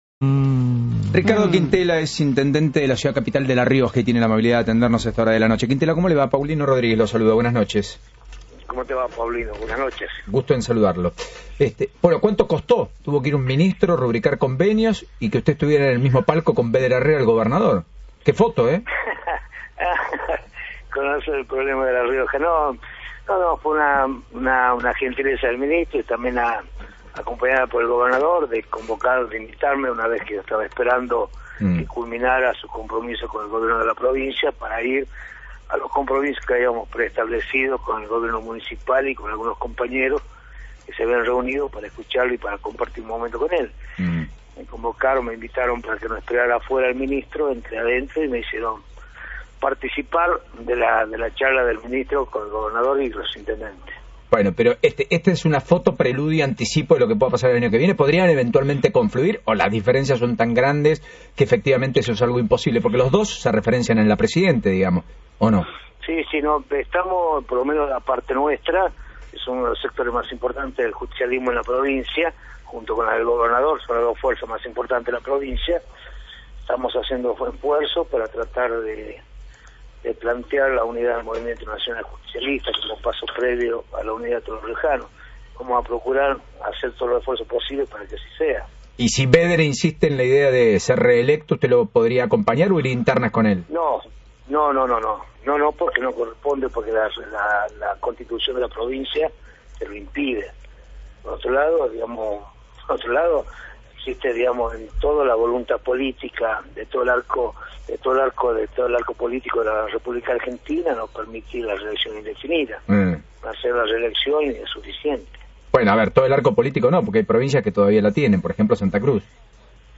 Quintela brindó declaraciones por radio Continental, luego que el ministro del Interior y Transporte, Florencio Randazzo, visitó a La Rioja.
Declaraciones de Quintela en radio Continental